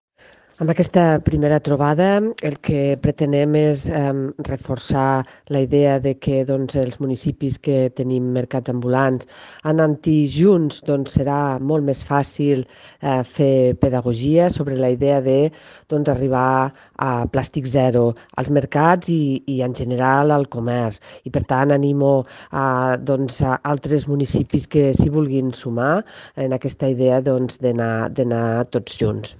tall-de-veu-de-la-regidora-marta-gispert-sobre-la-campanya-plastic-zero-als-mercats-ambulants